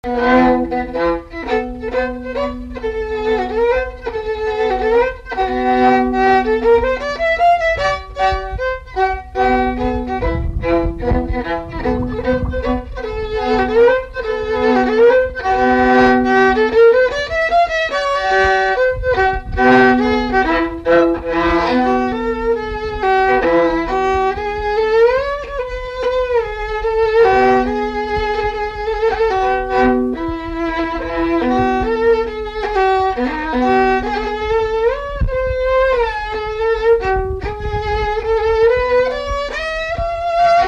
Chants brefs - A danser
Résumé instrumental
Pièce musicale inédite